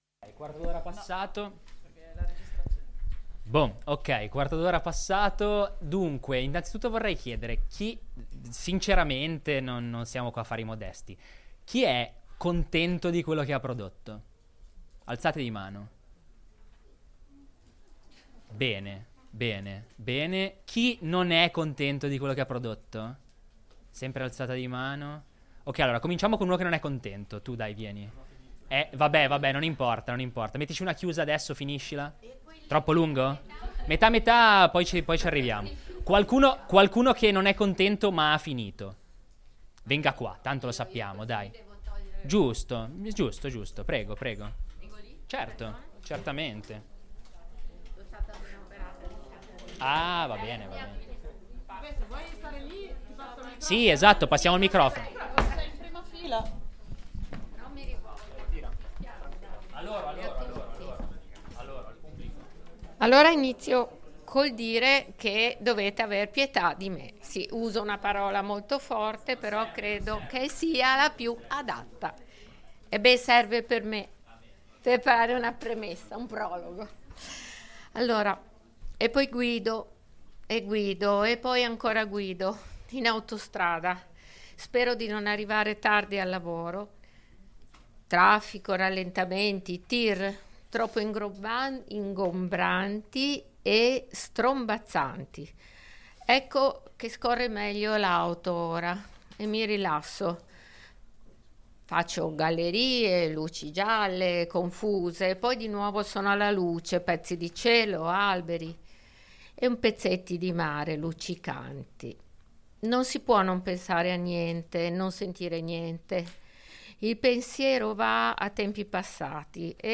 Potere alla Parola Scripta Manent - Lettura degli esercizi di scrittura creativa May 13 2025 | 00:36:14 1x 00:00 / 00:36:14 Subscribe Share RSS Feed Share Link Embed